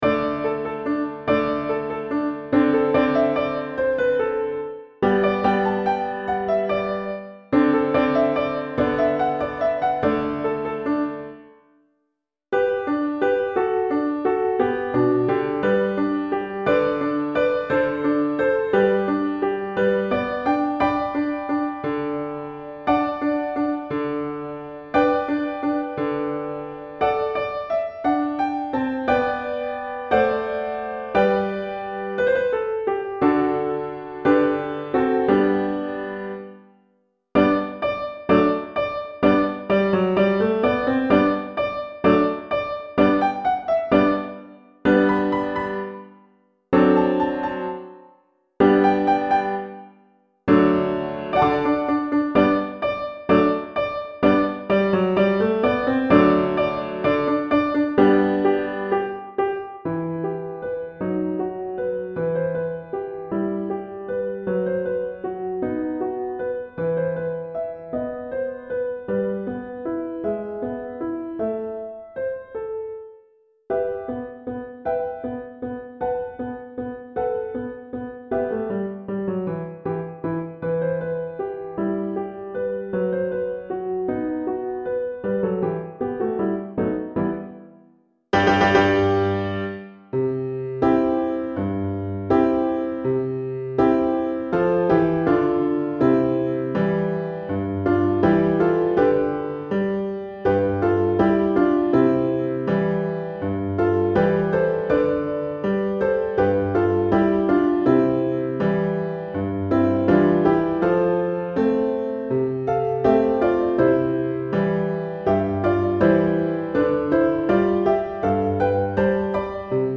alto & violoncelle